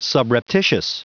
Prononciation du mot subreptitious en anglais (fichier audio)
Prononciation du mot : subreptitious